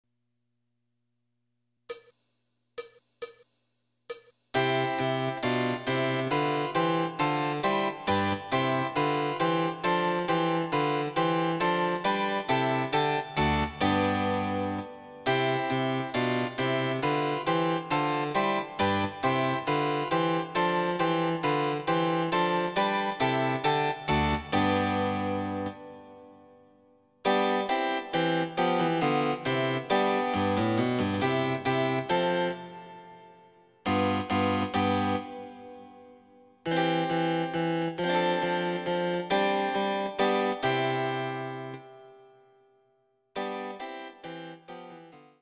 ★フルートの名曲をチェンバロ伴奏つきで演奏できる、「チェンバロ伴奏ＣＤつき楽譜」です。
試聴ファイル（伴奏）